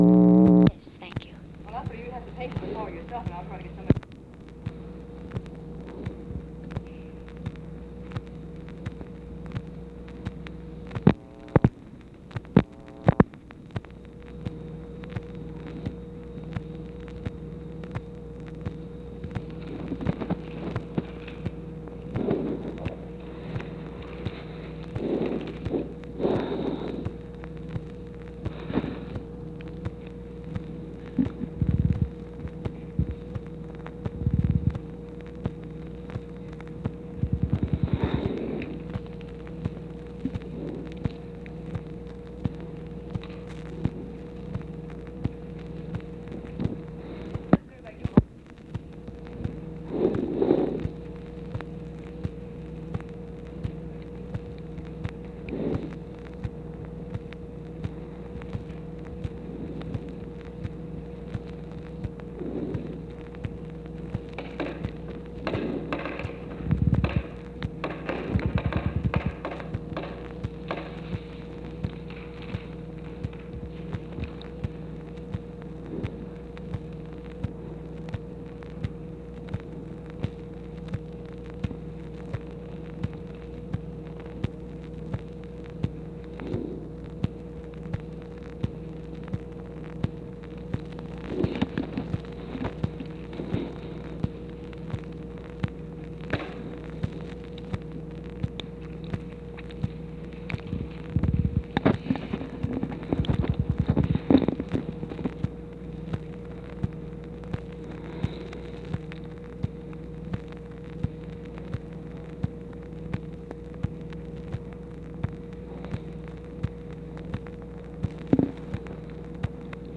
Conversation with OFFICE SECRETARY and TELEPHONE OPERATOR, July 10, 1964
Secret White House Tapes